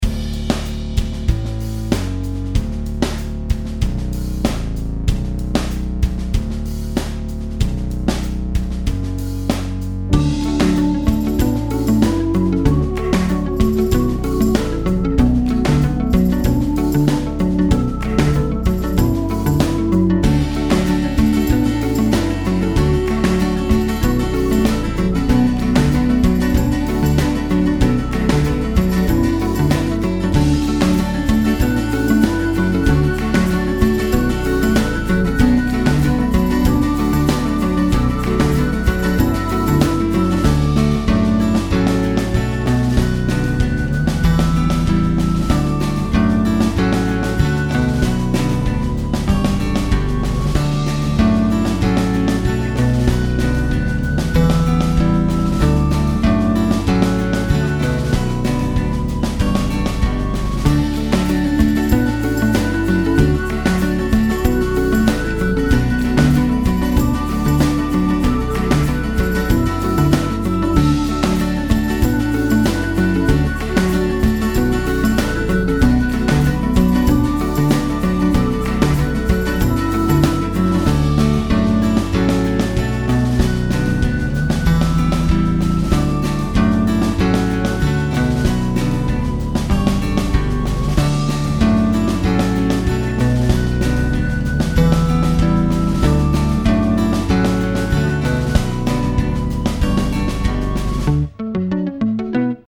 95 BPM
After a simple intro featuring drums and synthesizer,